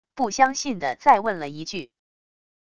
不相信的再问了一句wav音频